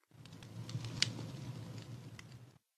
Minecraft Version Minecraft Version 1.21.5 Latest Release | Latest Snapshot 1.21.5 / assets / minecraft / sounds / block / campfire / crackle5.ogg Compare With Compare With Latest Release | Latest Snapshot
crackle5.ogg